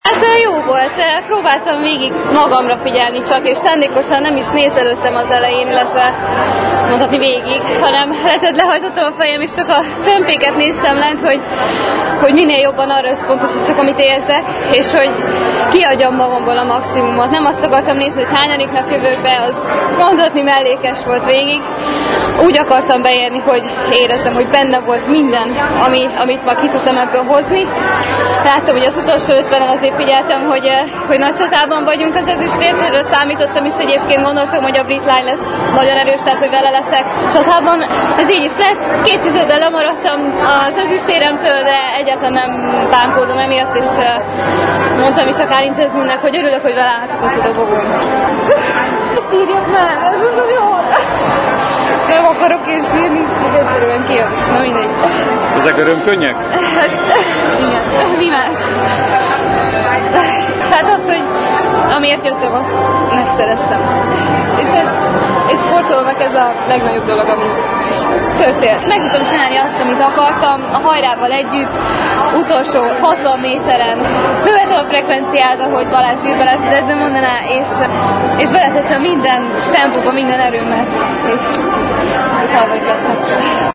A nap utolsó magyar érmét Kapás Boglárka szerezte, aki a 800 méteres gyorsúszásban új egyéni csúccsal (8:16.37) lett bronzérmes, az amerikai Katie Ledecky mögött, aki világcsúcsot úszott (8:04.79) és a brit Jazz Carlin mögött. Kapás Boglárka könnyeivel küszködött: